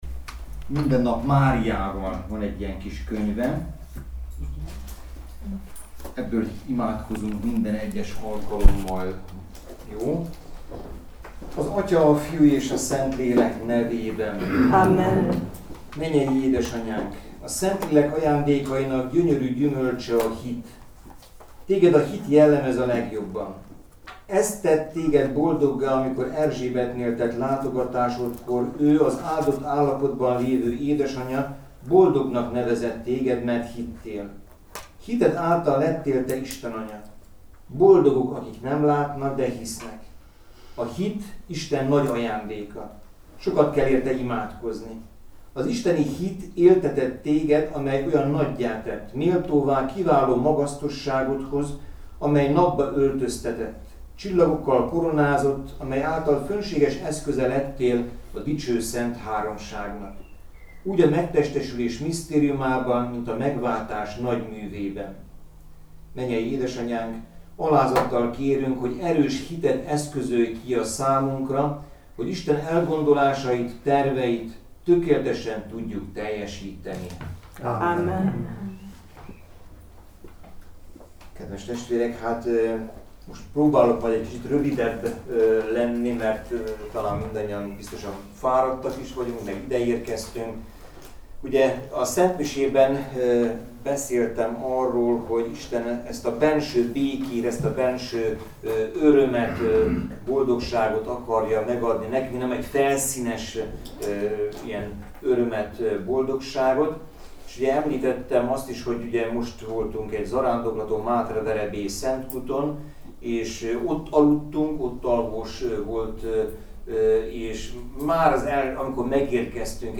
lelkigyakorlatot tartott a Szűzanyáról a leányfalui Szent Gellért lelkigyakorlatos házban.
Az elhangzott elmélkedések az alábbiakban meghallgathatóak: